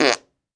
cartoon_squirt_03.wav